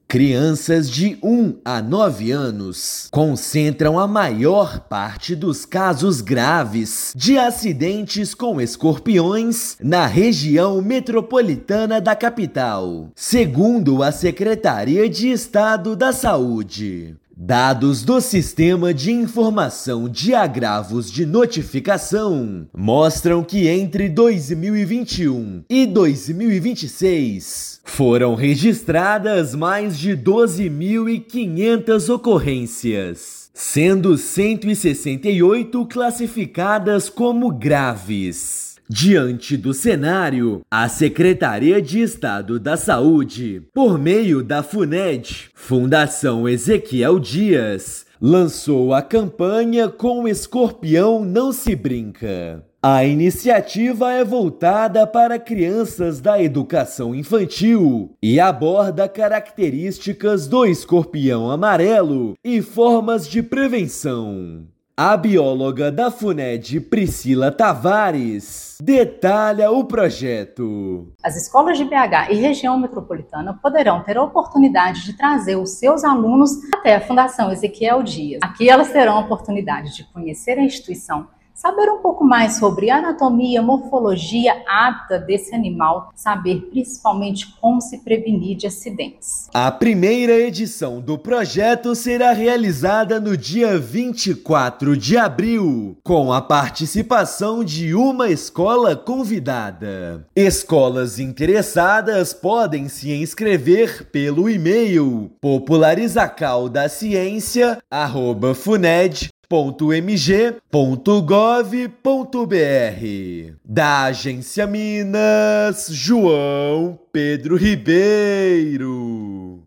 Iniciativa leva educação em saúde para escolas e prioriza regiões com maior incidência, com foco em prevenção e informação acessível. Ouça matéria de rádio.